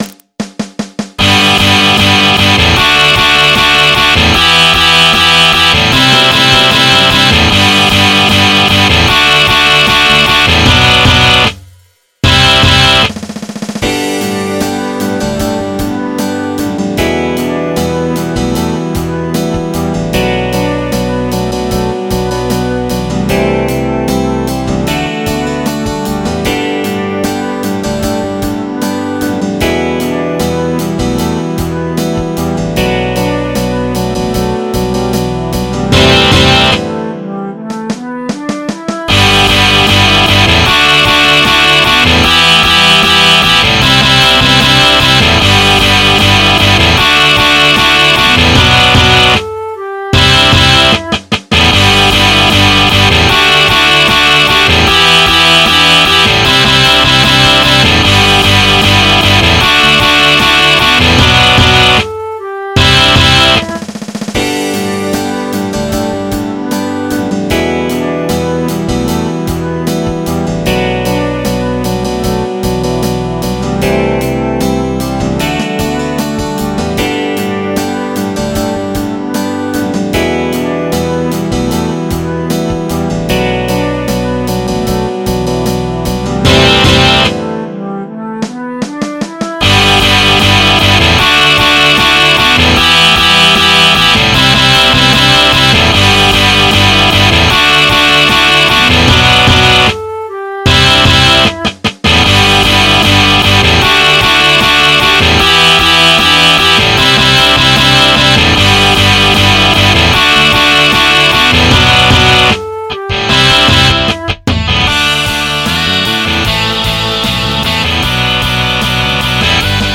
MP3 (Converted)
Midi file